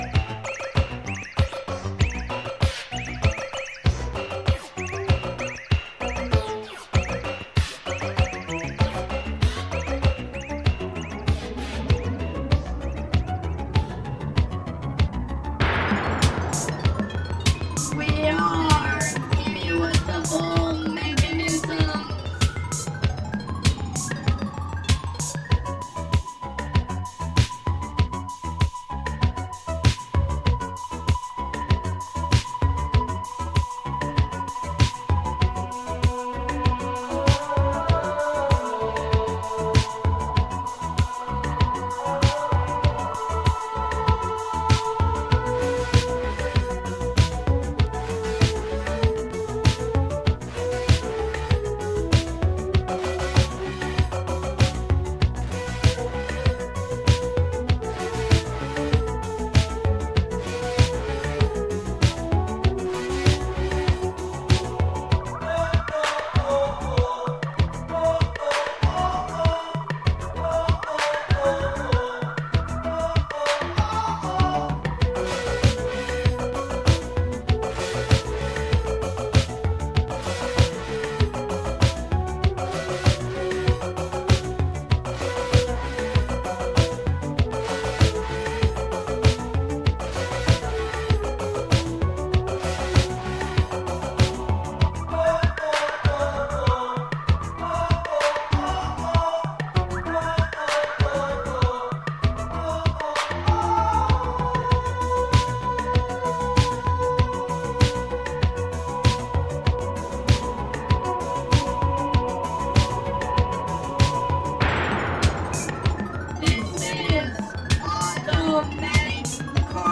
Synth classic
Remastered from the original tapes